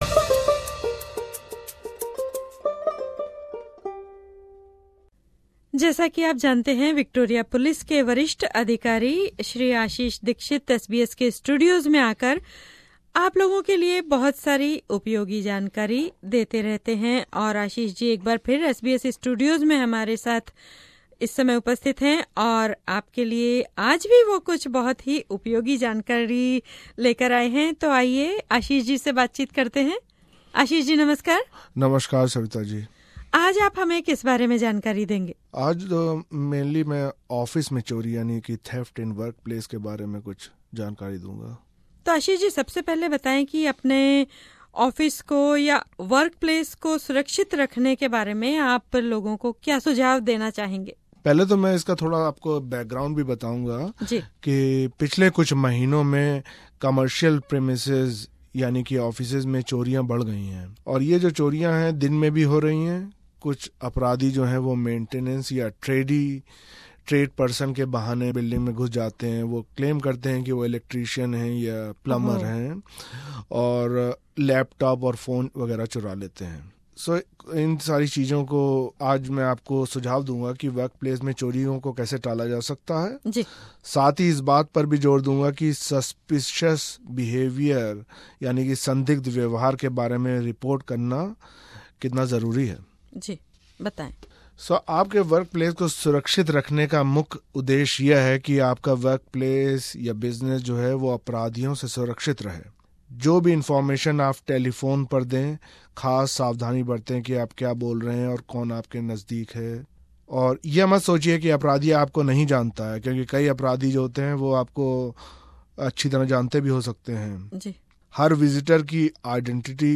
वे हिन्दी कार्यक्रम के नियमित अतिथि हैं, इस बातचीत में उन्होंने हमें कार्यस्थल सुरक्षा के बारे में कुछ महत्वपूर्ण सुझाव दिये हैं।